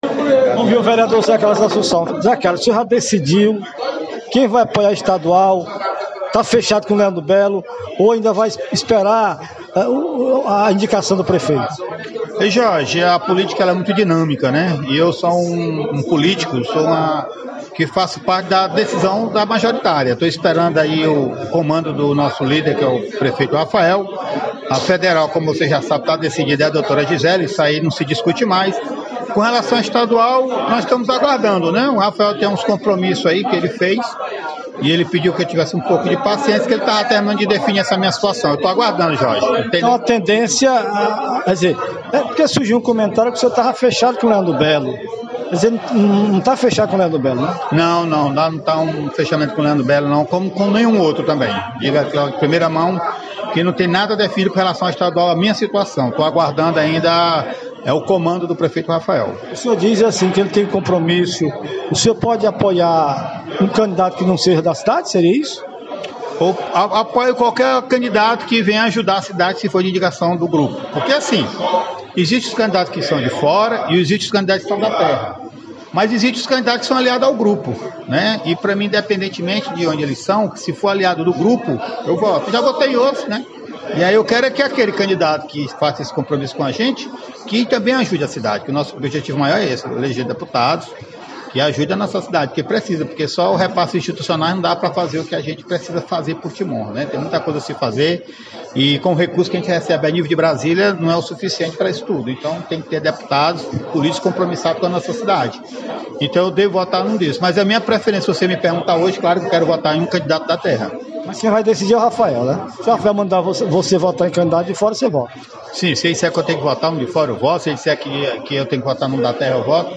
A s declarações do vereador José Carlos, foram dadas durante uma entrevista concedida ao Programa do Galo, da Radio Celestial FM.
Entrevista vereador José Carlos Assunçao